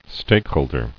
[stake·hold·er]